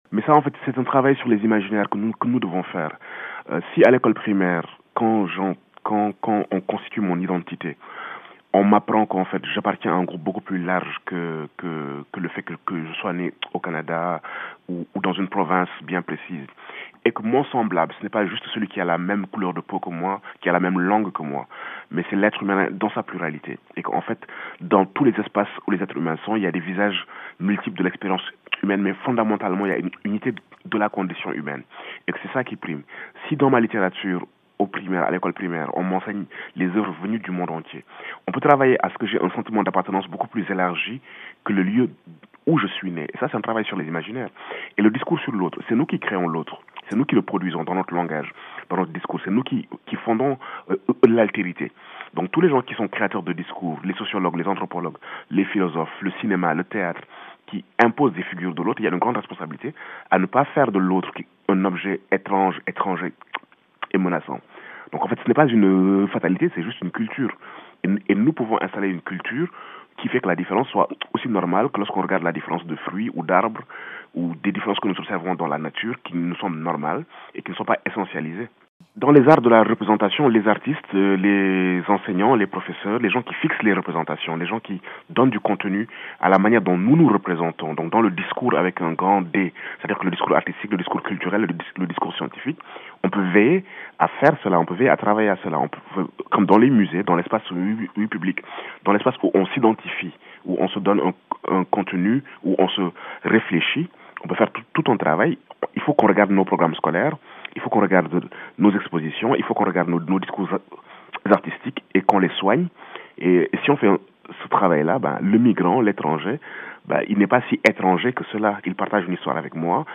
Dans cet extrait de la conversation que nous avons eue avec lui jeudi lors de son passage au Salon du livre de Montréal, il explique pourquoi les frontières n’ont plus de pertinence, sur tous les plans, même politique et économique.